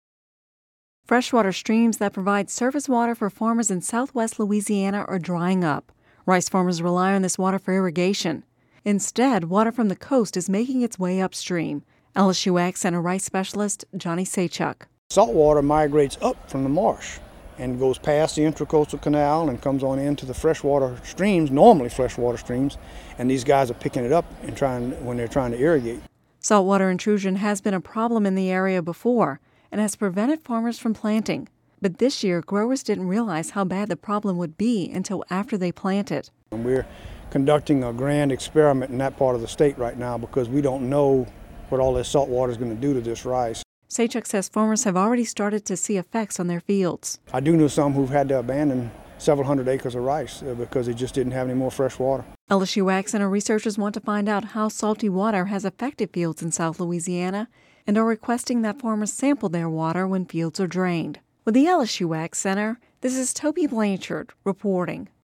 (Radio News 07/13/11) Freshwater streams that provide surface water for farmers in southwest Louisiana are drying up.